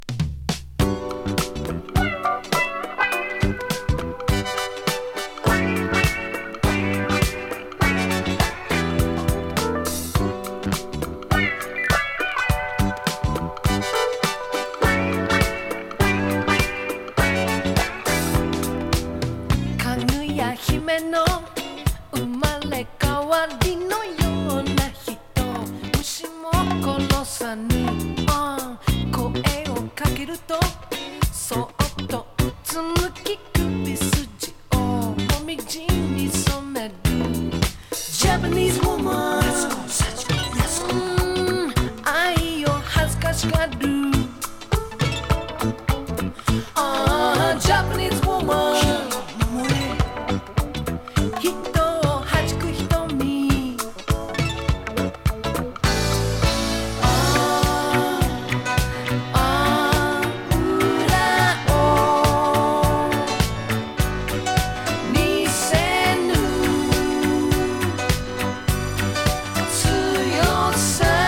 イントロから引き込まれるCity Soul！